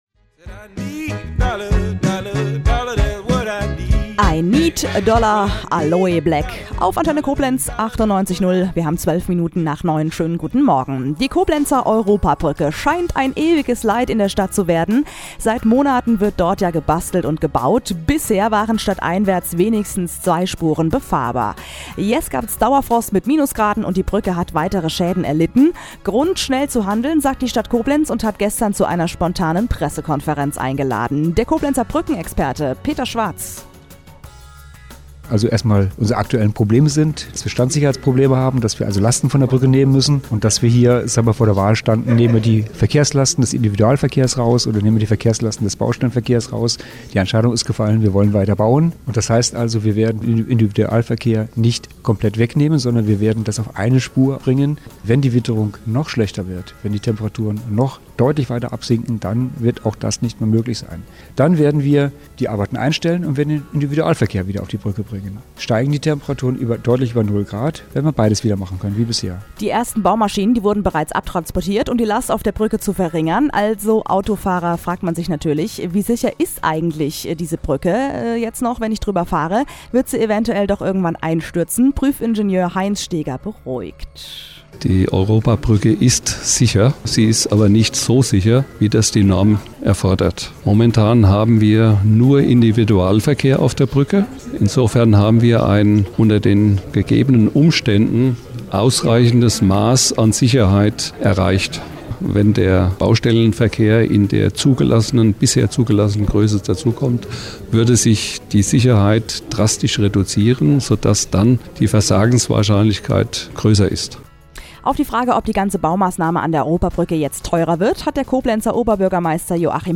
Mit diversen Interviews, u.a. mit OB Hofmann-Göttig